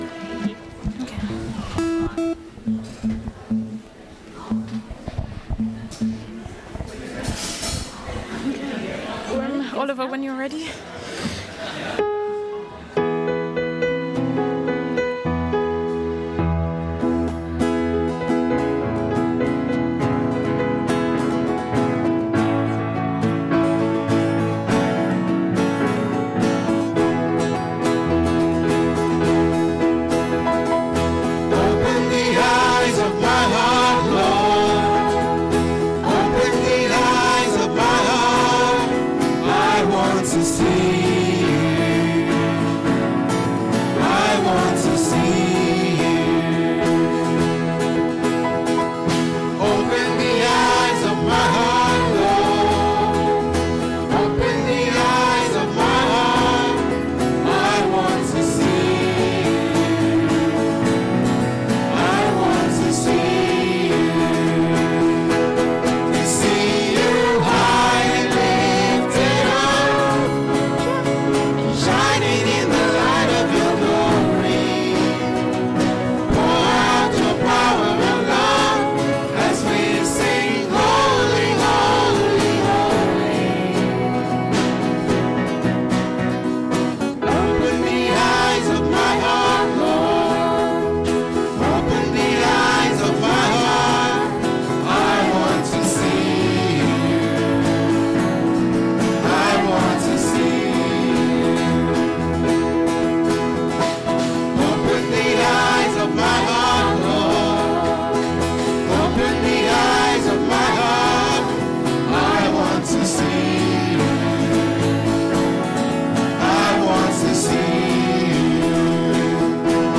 Join us for a service led by the youth of the church.
Service Audio